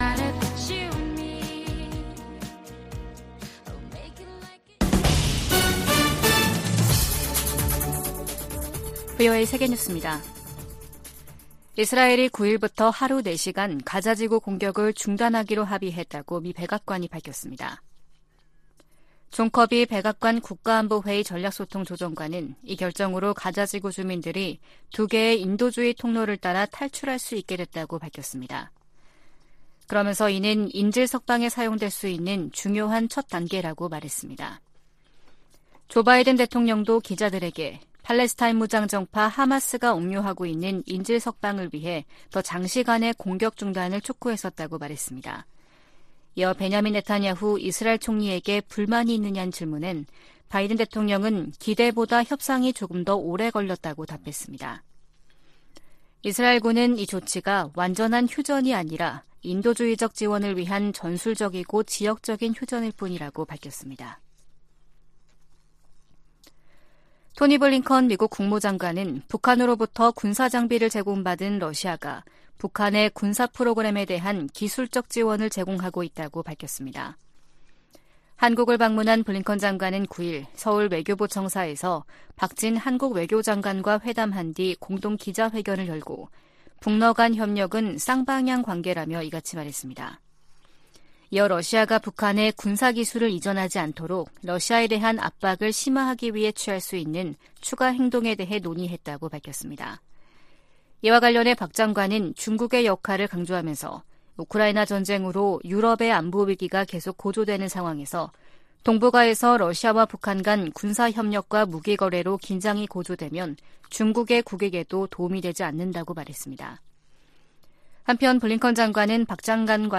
VOA 한국어 아침 뉴스 프로그램 '워싱턴 뉴스 광장' 2023년 11월 10일 방송입니다. 토니 블링컨 미 국무장관과 박진 한국 외교장관이 9일 서울에서 만나 북러 군사협력 문제 등 현안을 논의했습니다. 백악관은 미국이 한반도 비상상황 대비를 위해 늘 노력하고 있다며, 한국의 중동 개입은 '주권적 결정' 사안이라고 강조했습니다. 주요7개국(G7) 외교장관들이 북한의 지속적인 대량살상무기 개발과 러시아로의 무기 이전을 강력히 규탄했습니다.